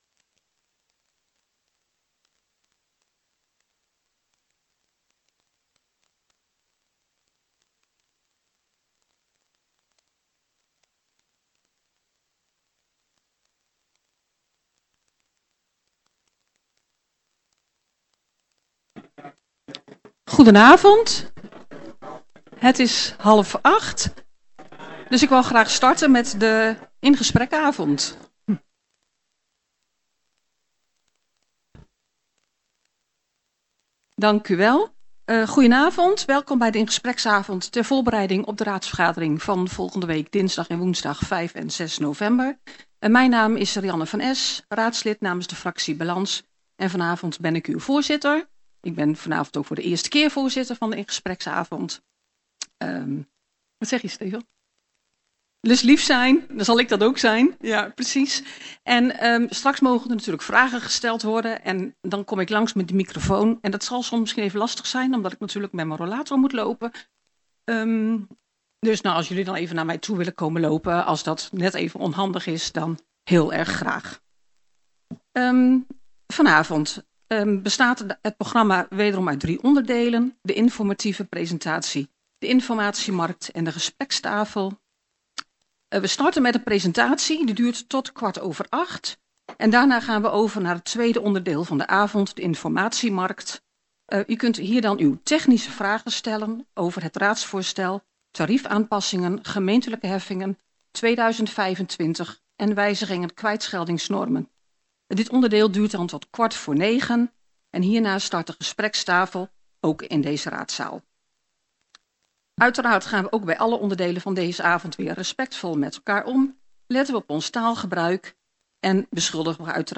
Deze bijeenkomst vindt plaats in het gemeentehuis.